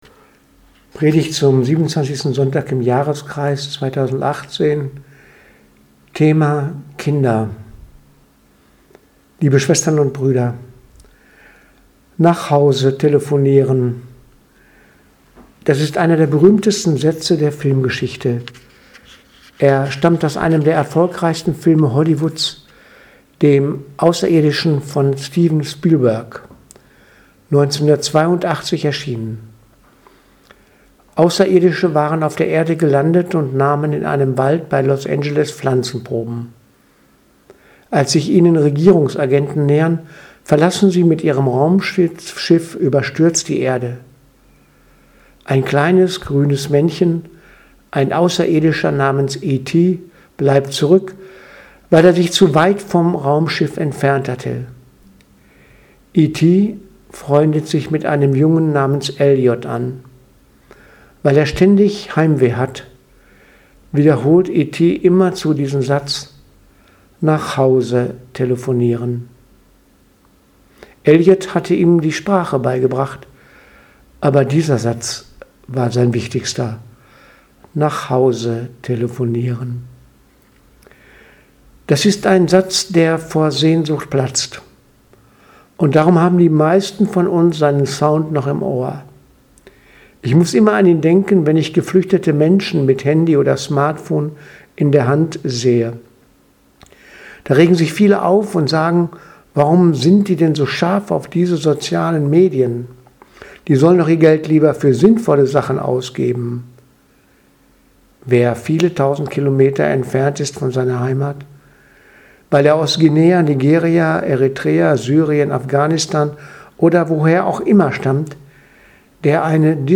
Predigt vom 7.10.2018 – Kinder